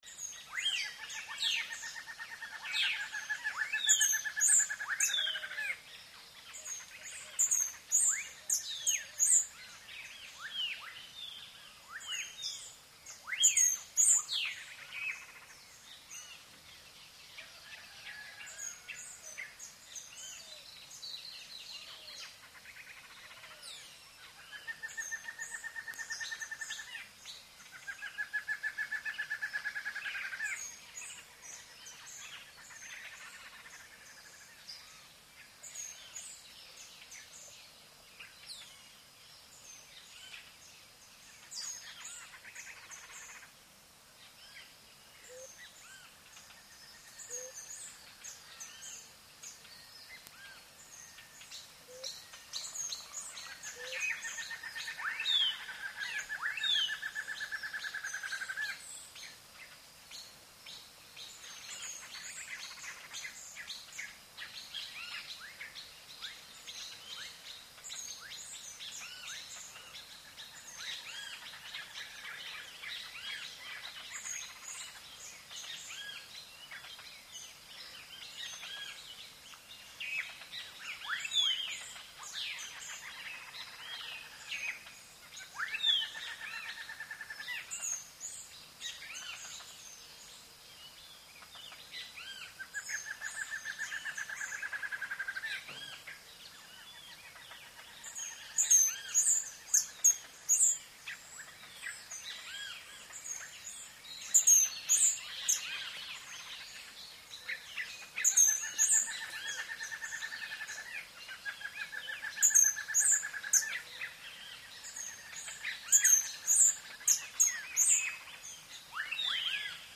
Belize jungle at dawn, hummingbird song in foreground ( Cayo district )